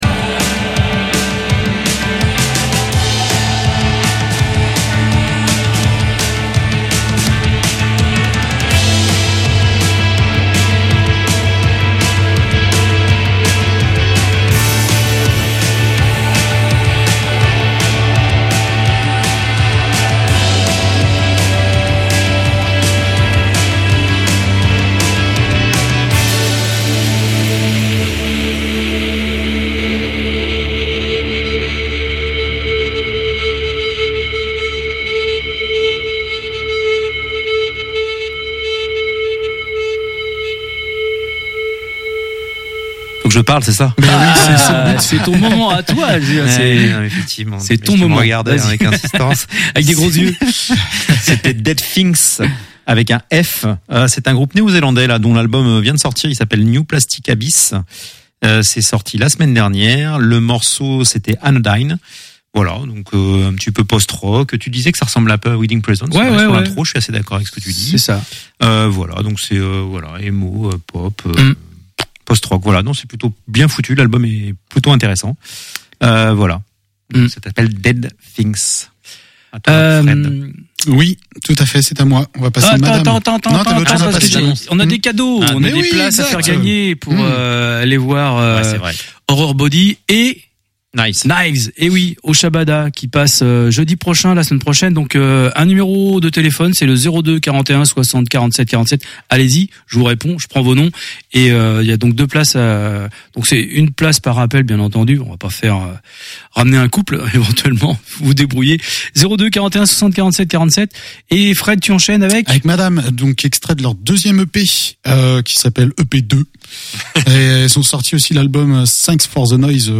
Ça Dégouline Dans Le Cornet est une émission de radio et une webradio montée de toutes pièces par des amateurs et passionnés de musique noise, punk, garag